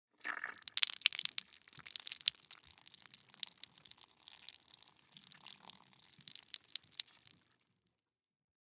Звуки лимона, лайма
Откройте для себя яркие звуки лимона и лайма: сочное разрезание, брызги сока, хруст свежих долек.
Звук вичавлювання соку з апельсина при стисканні фрукта в руці